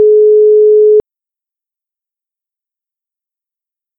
ringing.mp3